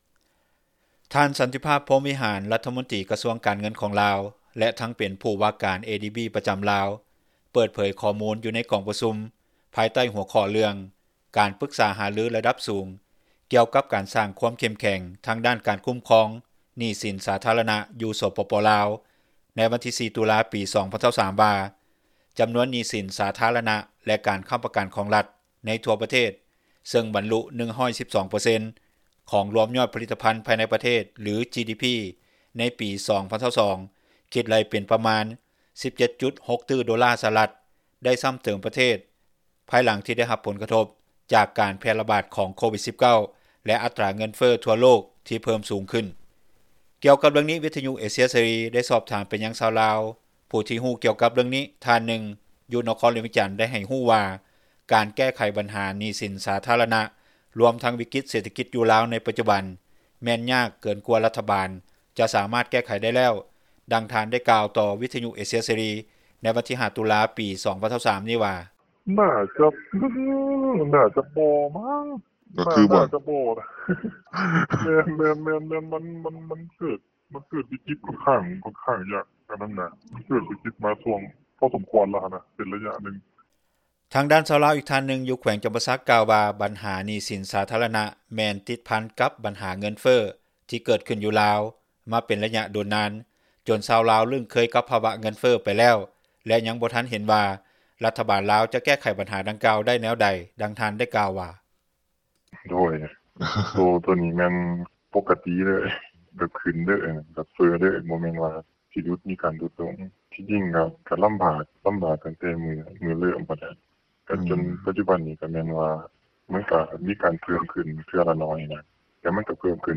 ກ່ຽວກັບເຣື່ອງນີ້, ວິທຍຸເອເຊັຽເສຣີ ໄດ້ສອບຖາມໄປຍັງ ຊາວລາວ ຜູ້ທີ່ຮູ້ກ່ຽວກັບເຣື່ອງນີ້ ທ່ານນຶ່ງ ຢູ່ນະຄອນຫຼວງວຽງຈັນ ໄດ້ໃຫ້ຮູ້ວ່າ ການແກ້ໄຂບັນຫາໜີ້ສິນ ສາທາຣະນະ ລວມທັງວິກິດເສຖກິຈ ຢູ່ລາວໃນປັດຈຸບັນ ແມ່ນຍາກເກີນກວ່າທີ່ຣັຖບານ ຈະສາມາດແກ້ໄຂໄດ້ແລ້ວ.